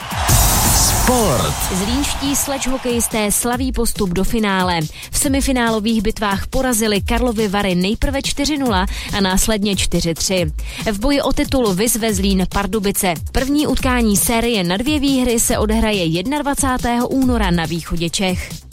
Reportáž z 2. semifinálového utkání mezi SHK Lapp Zlín a SKV Sharks Karlovy Vary.